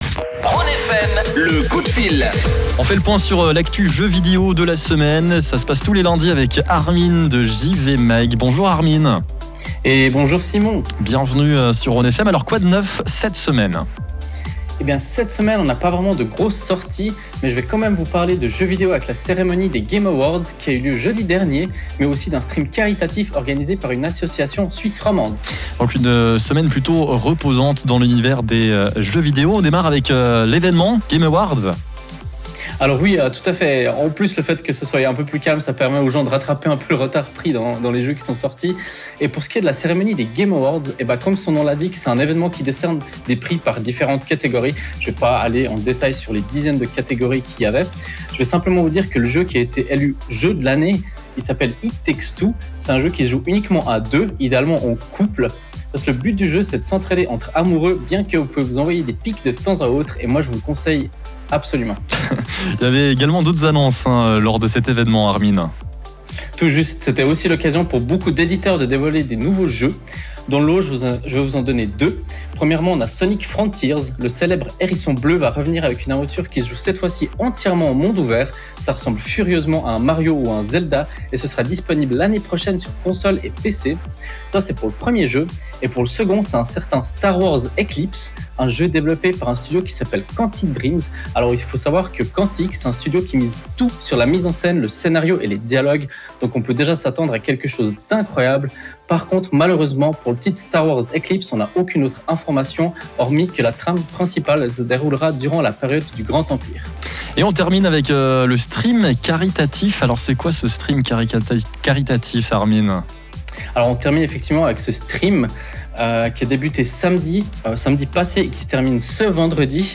Après une petite semaine d’absence, notre chronique gaming revient en direct sur Rhône FM. Au programme aujourd’hui j’ai décidé de vous parler de la cérémonie des Game Awards, mais aussi de l’événement Gamers à cœur.